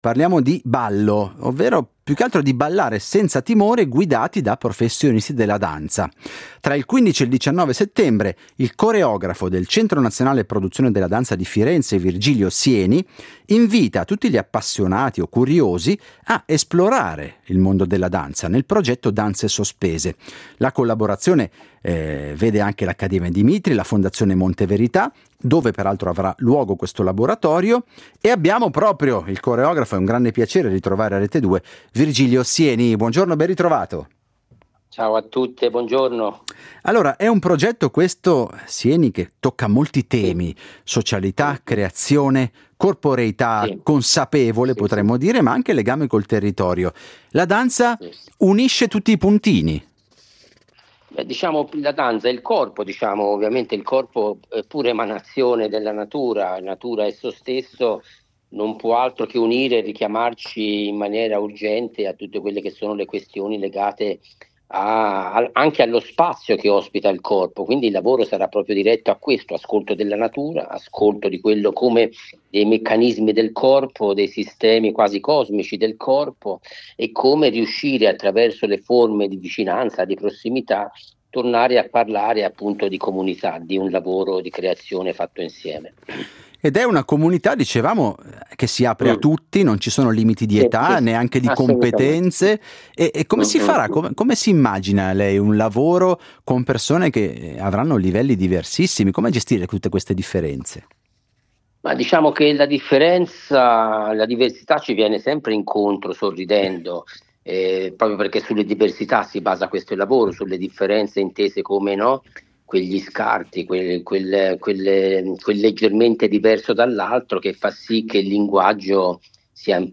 Il coreografo Virgilio Sieni
Ne abbiamo parlato con il coreografo del progetto Virgilio Sieni.